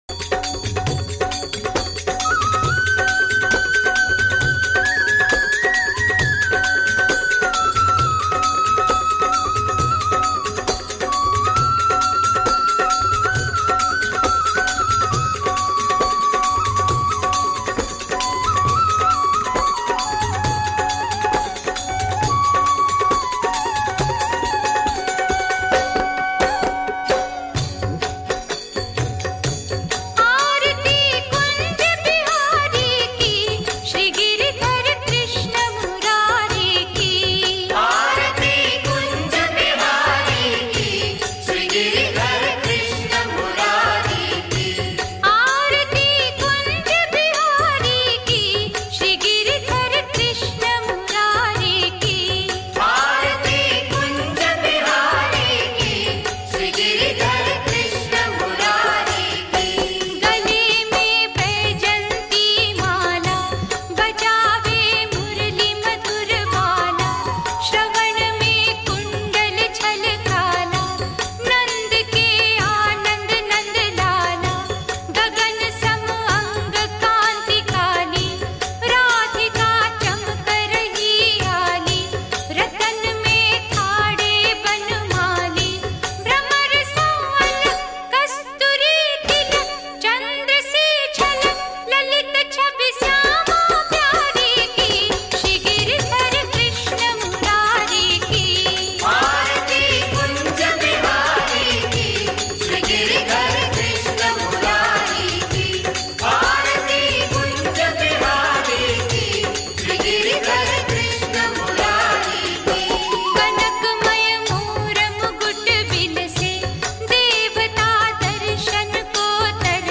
Krishna Bhajan
Devotional Songs